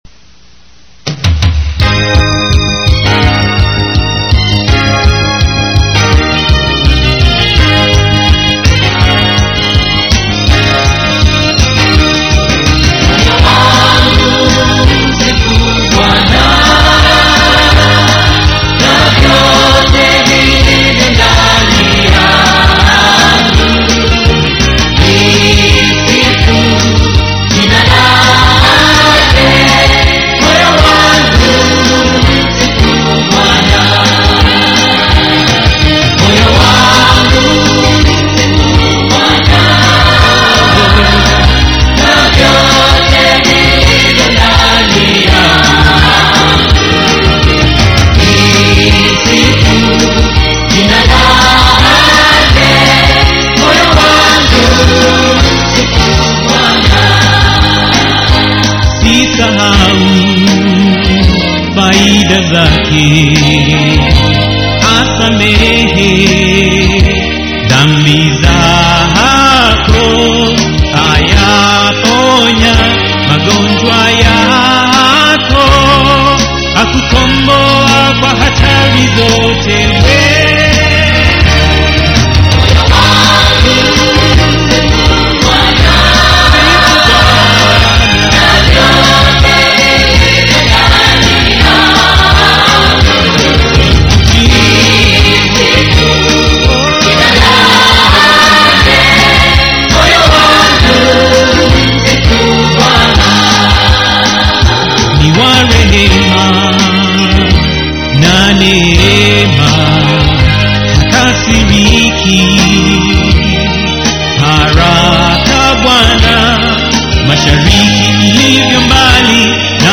Swahili Songs
This music is all Christian music.